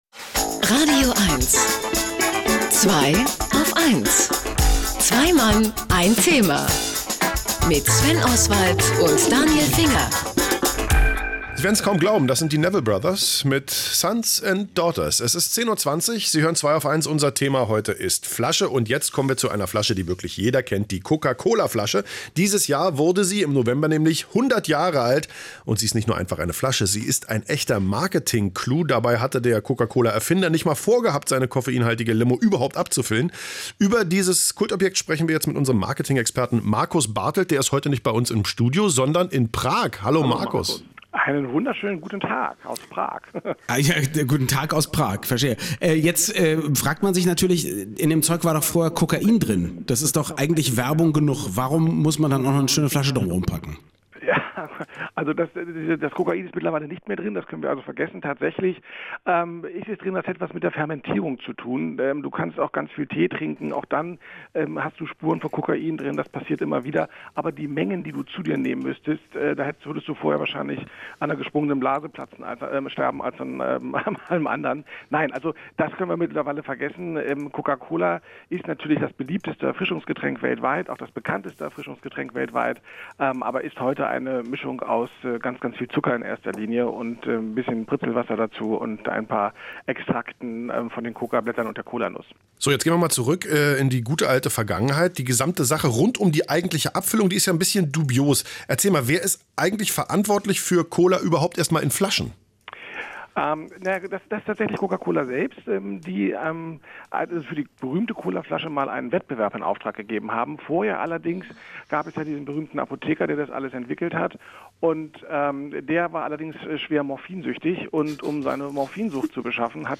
Und so haben sie mich bei meinem Adventsausflug im traumhaften Prag erwischt, um mich für ihre radioeins-Sendung zum Thema „Flaschen“ zu befragen: